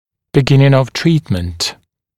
[bɪ’gɪnɪŋ əv ‘triːtmənt][би’гинин ов ‘три:тмэнт]начало лечения